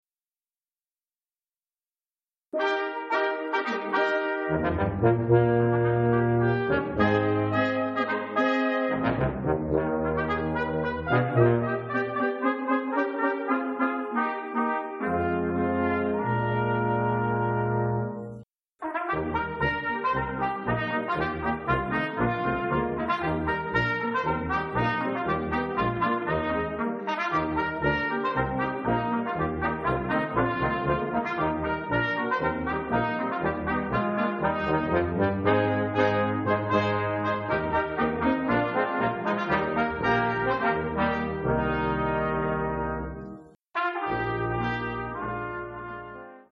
Seton Performance Series - 1/18/2004